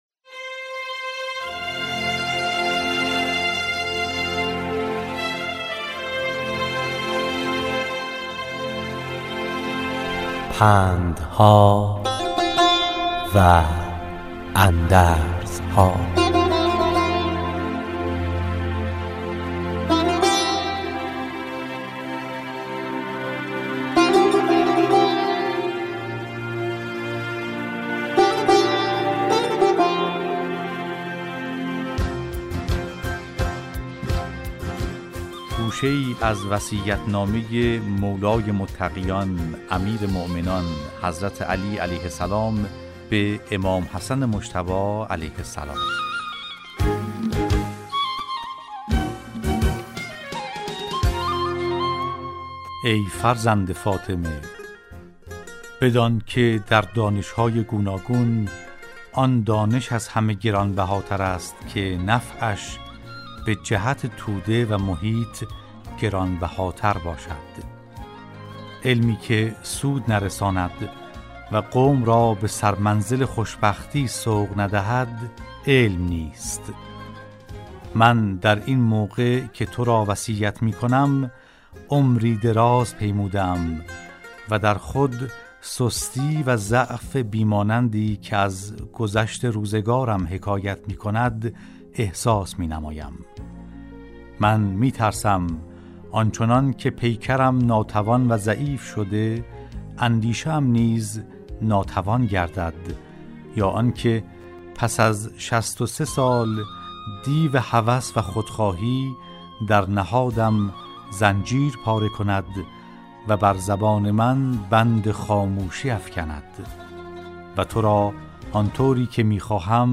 راوی برای شنوندگان عزیز صدای خراسان، حکایت های پندآموزی را روایت می کند .